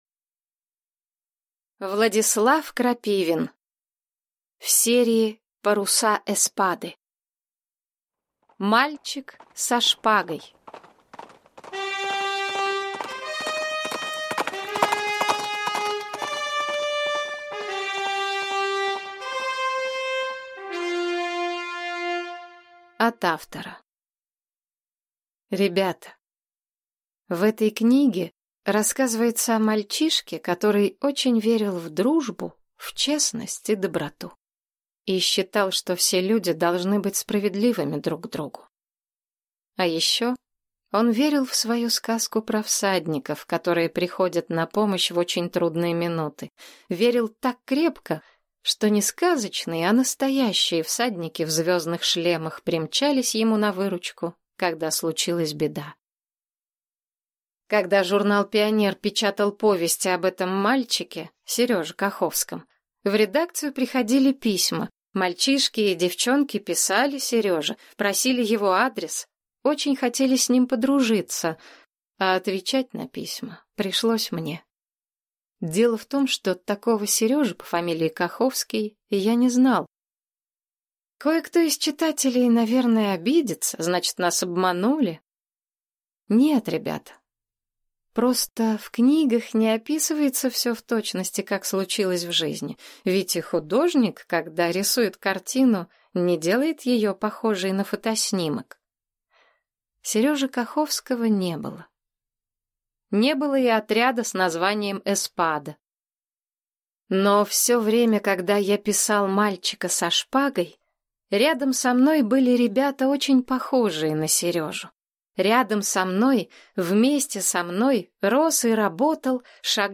Аудиокнига Мальчик со шпагой | Библиотека аудиокниг